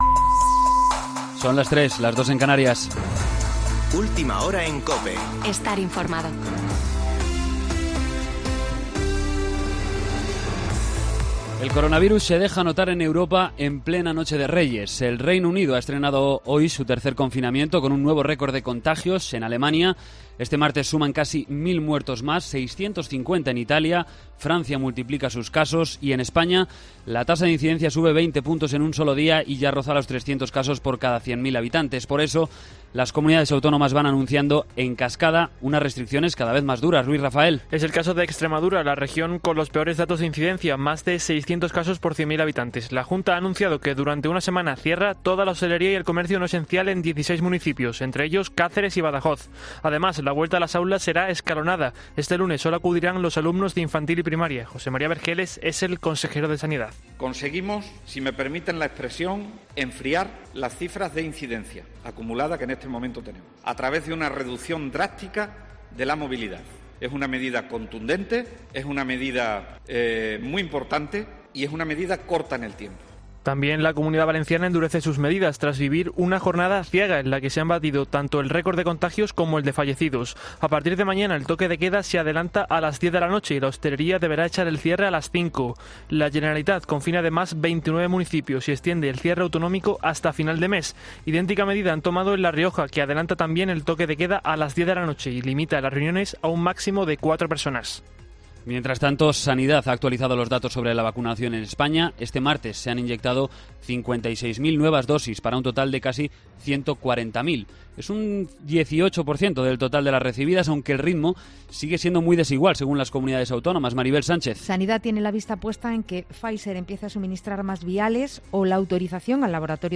Boletín de noticias COPE del 6 de enero de 2020 a las 03.00 horas